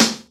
kits/RZA/Snares/WTC_SNR (1).wav at 32ed3054e8f0d31248a29e788f53465e3ccbe498